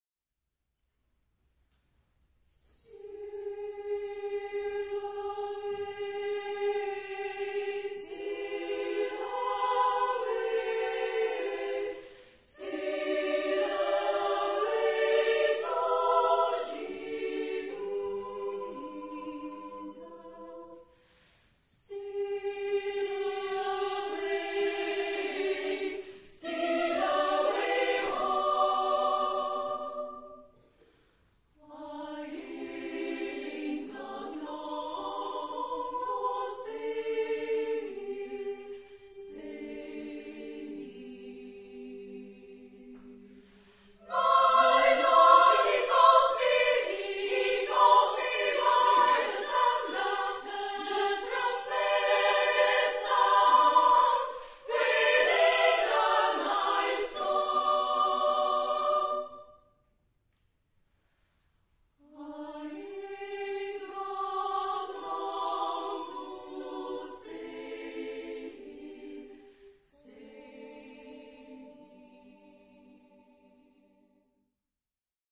Genre-Style-Forme : Sacré ; Spiritual Afro-Américain
Type de choeur : SSA  (3 voix égales de femmes )
interprété par Choeur féminin Makeblijde